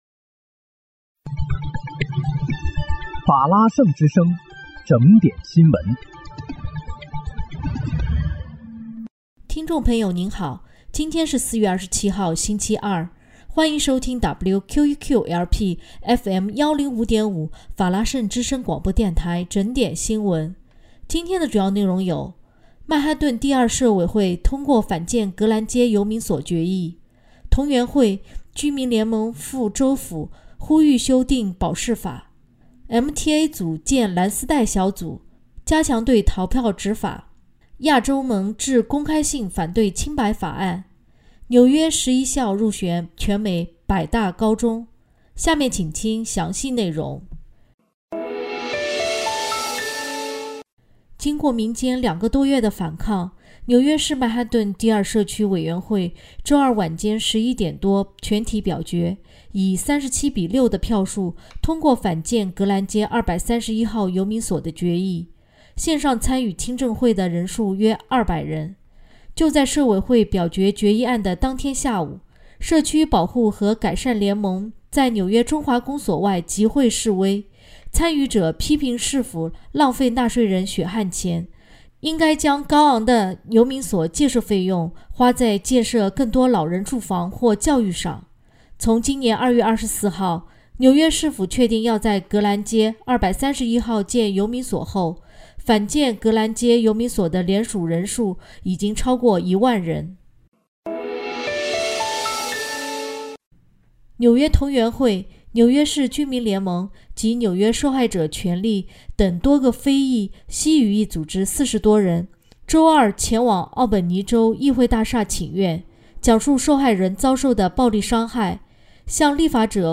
4月27日（星期三）纽约整点新闻